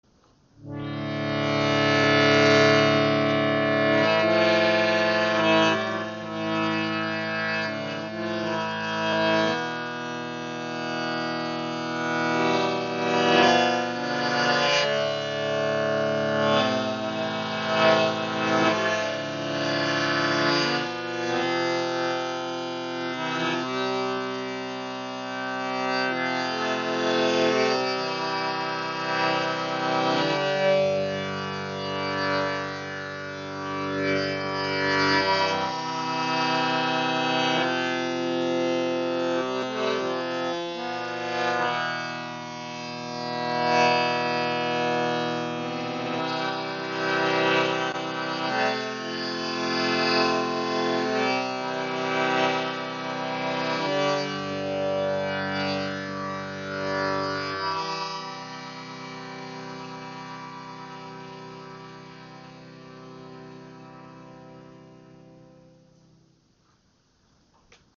Harmonium | 3½ Oktaven | extra grosse Luftkammer | Teakholz | 432 Hz im Raven-Spirit WebShop • Raven Spirit
Klangbeispiel
Dieses sehr schön verarbeitete Harmonium aus edlem Tekholz hat eine Klangbreite von 3½ Oktaven und eine sehr schöne Klangfarbe.
Jeder Ton ist mit je einer Zunge in mittlerem und tieferem Register ausgestattet, sodass sich ein voller, tragender Klang ergibt. Mit Hilfe der Registerzüge, die grossen Knöpfe, lassen sich die beiden Register auch einzeln spielen.